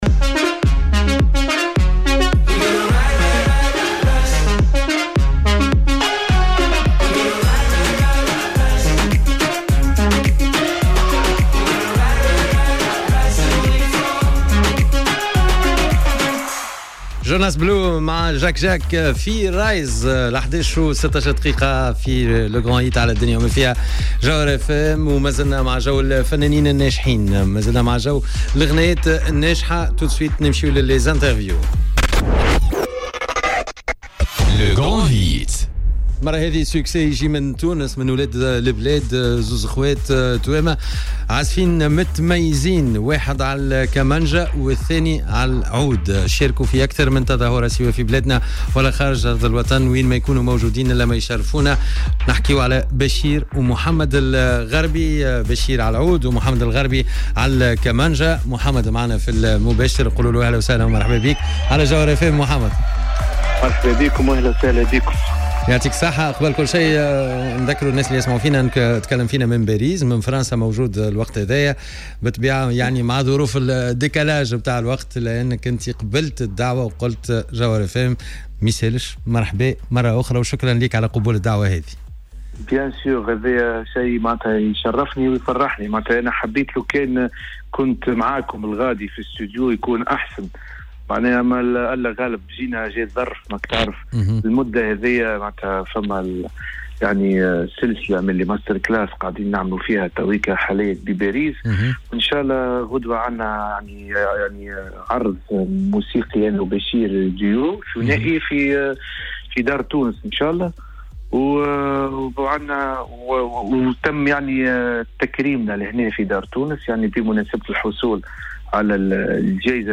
في مداخلة هاتفية من باريس